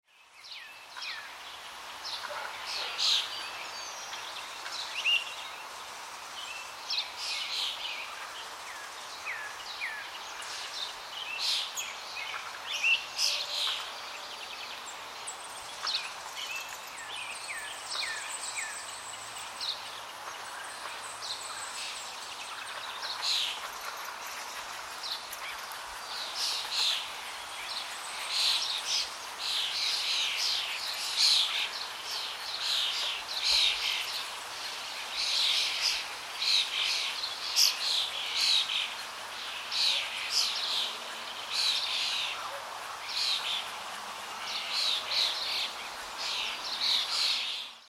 دانلود آهنگ آب 2 از افکت صوتی طبیعت و محیط
جلوه های صوتی